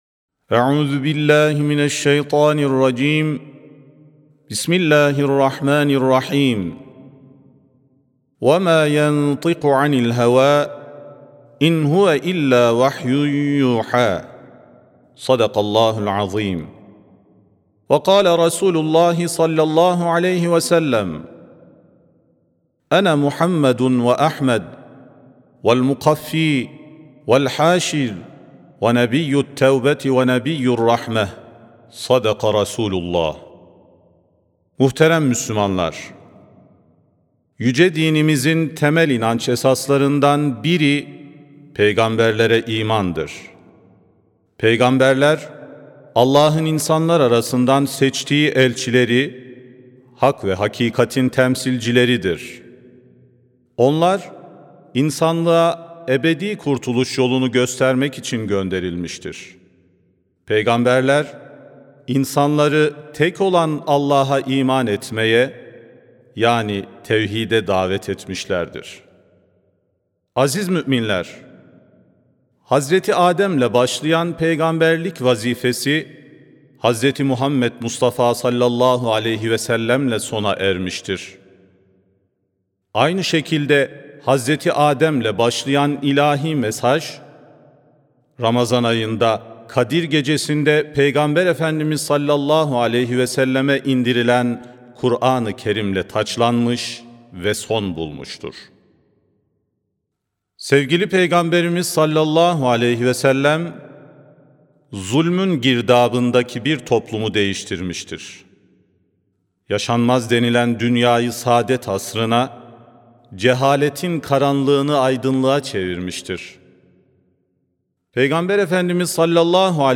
Sesli-Hutbe-Hak-ve-Hakikatin-Temsilcileri.mp3